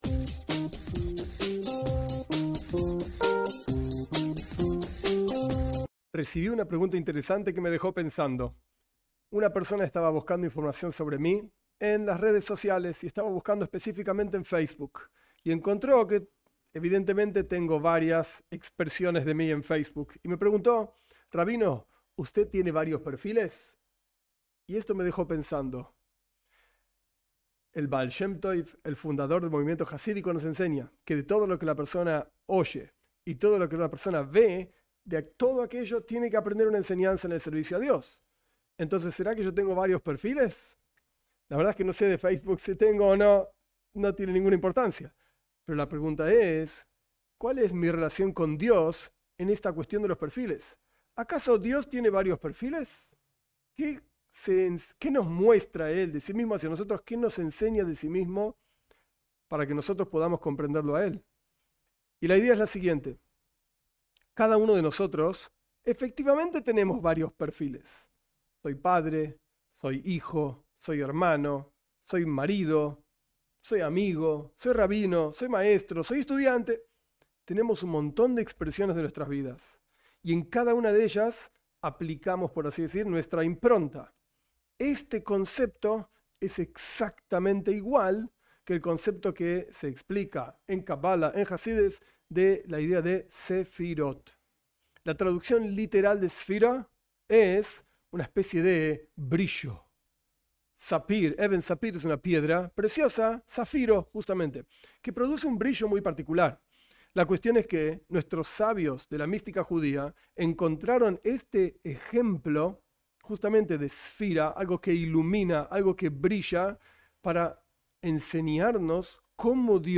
Esta clase corta explica, con ejemplos, de qué se trata esta doctrina de la mística judía, ampliamente explicada en la Cabala y el Jasidut.